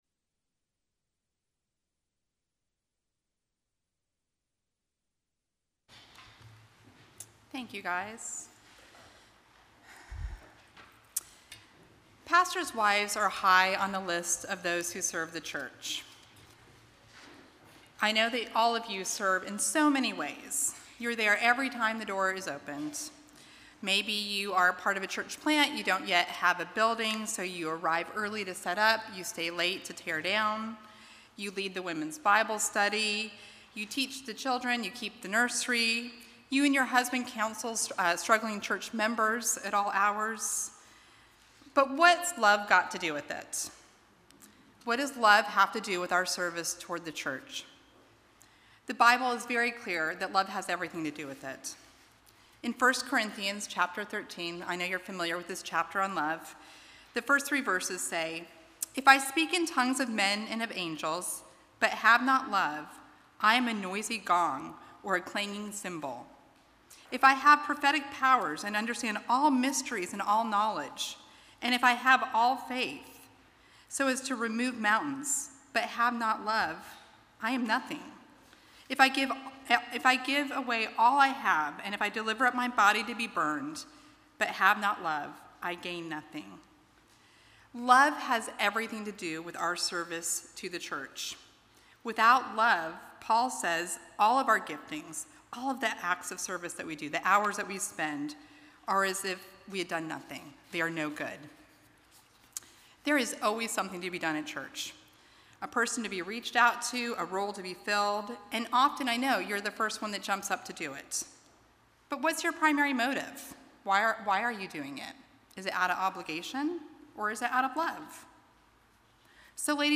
Audio recorded at Feed My Sheep for Pastors Wives Conference 2022.